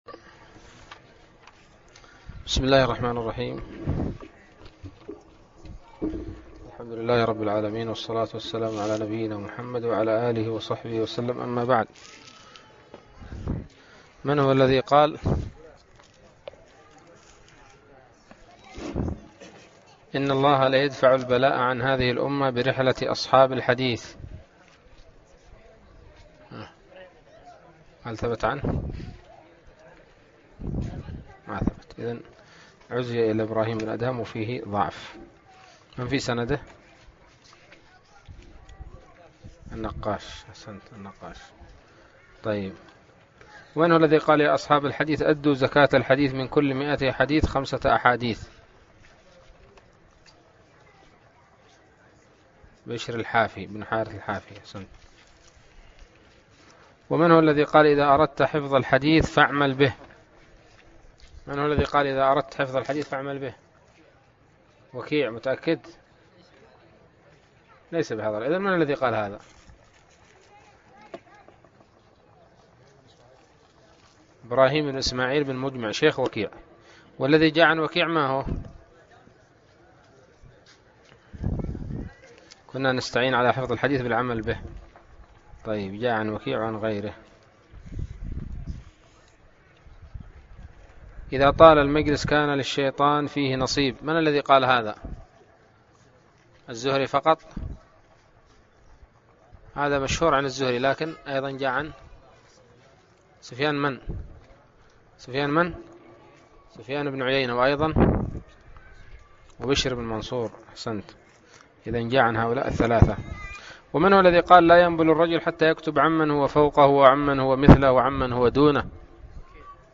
الدرس السابع والأربعون من الباعث الحثيث